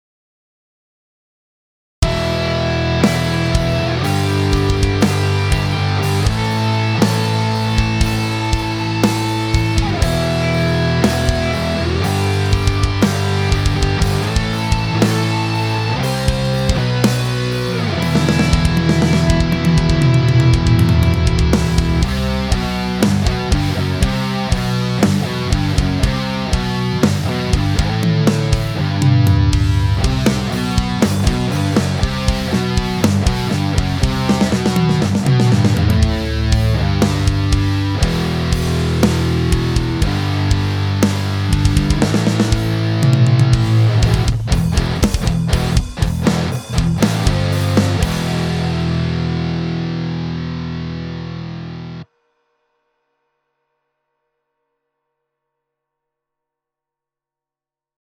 Ich spiel selber Gitarre und Bass ein (gerade über Plugins wie Amped Root) und pack dann ein Schlagzeug von EZDrummer drauf.